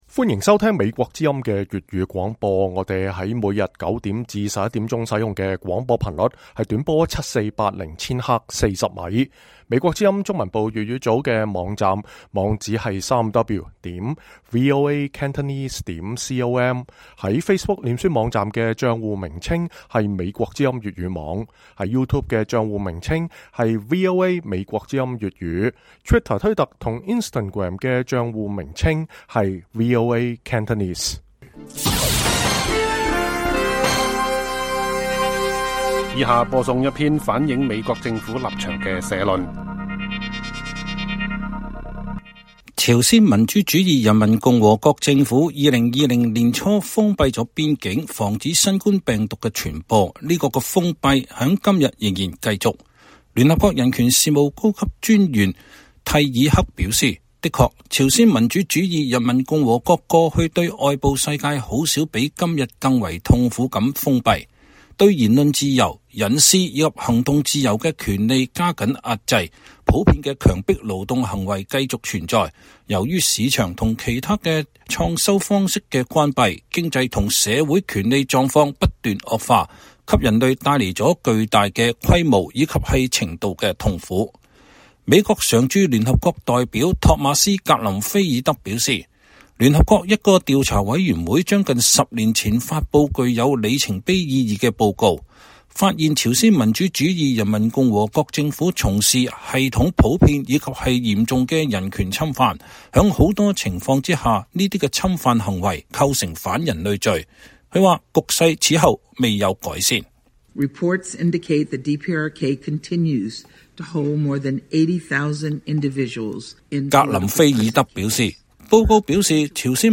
以下是一篇反映美國政府政策立場的社論：